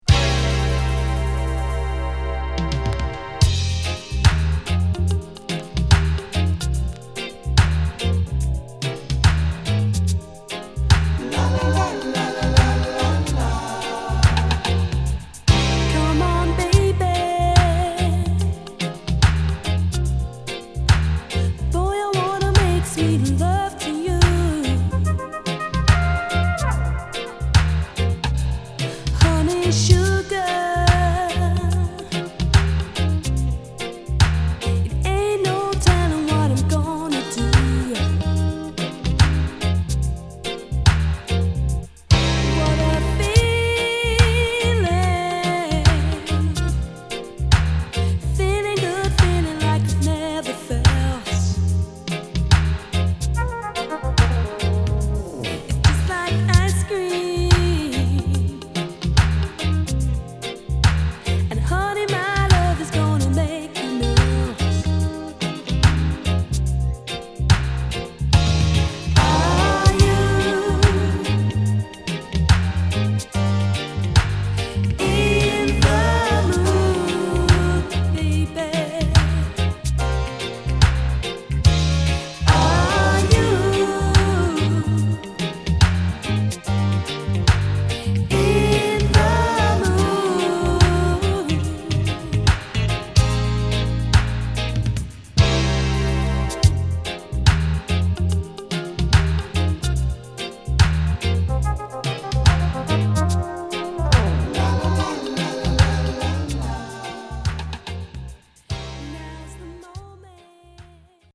オリジナルのゆったりした雰囲気を生かしつつ、よりソフトなラバーズ・ロックに仕上げたナイスカヴァー！